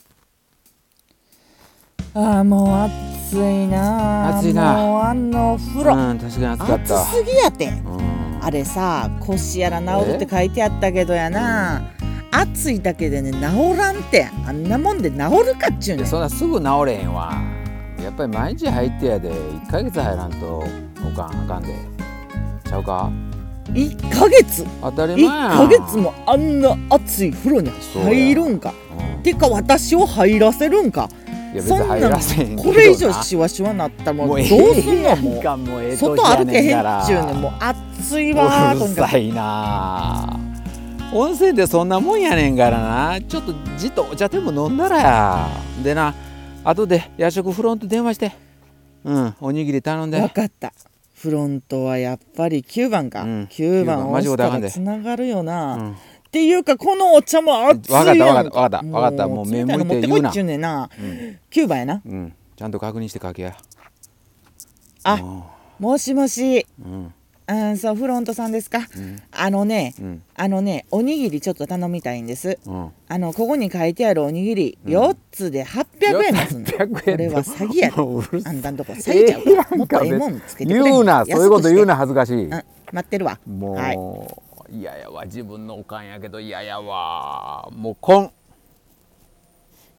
声劇 オカンと温泉旅行♨